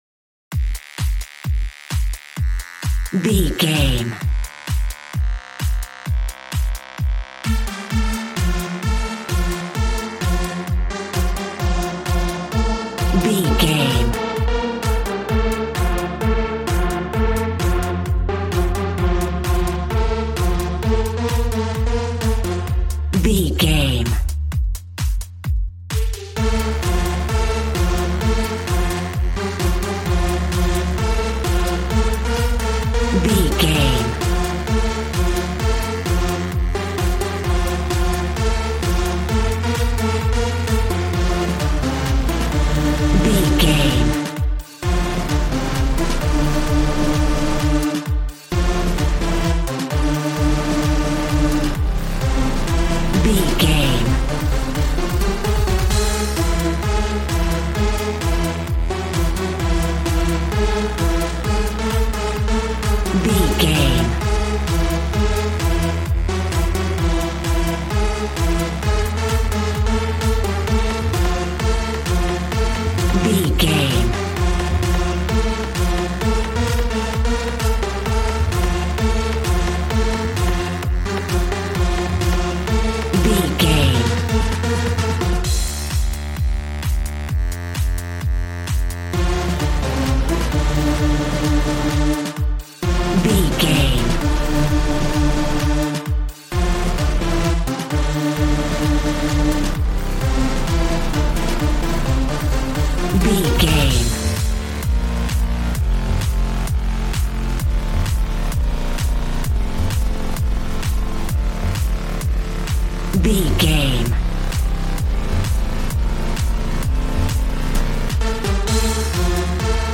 Ionian/Major
Fast
groovy
energetic
synthesiser
drums